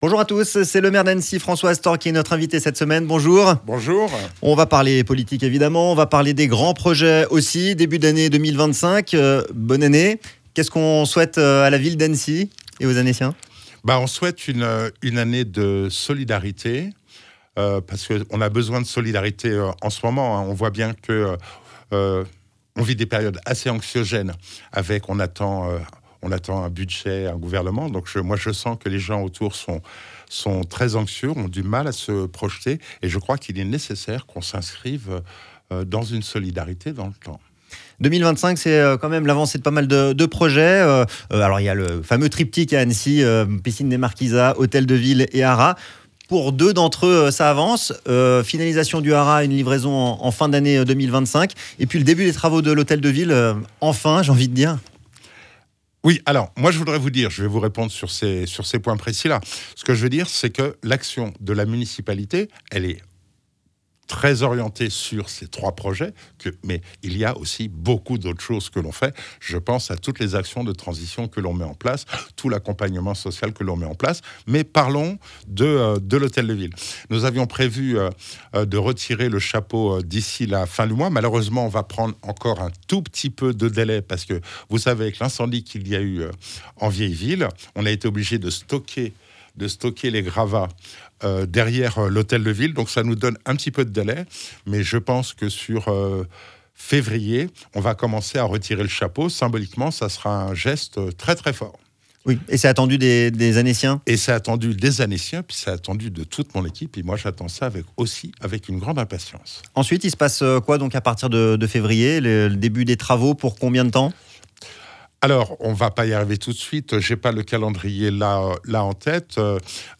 François Astorg, maire d'Annecy était l'Invité de la Rédac sur ODS Radio. Il revient sur les priorités de la ville.